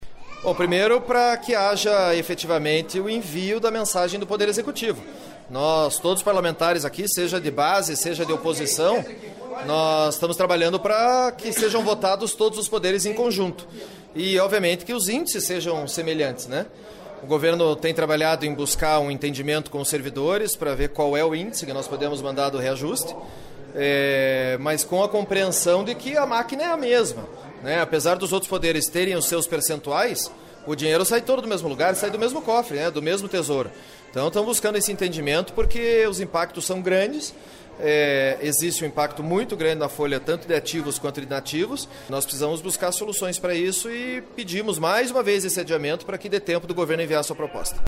Ouça a entrevista com o líder do Governo, deputado Pedro Lupion (DEM).